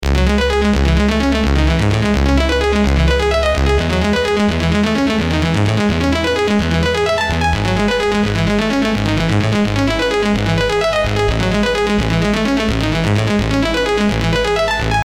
Mind-Bending Synths for High Energy Drops + Melodies for Heartfelt Tunes.
Synth_Loop_19_-_128BPM_C_min_Arp.mp3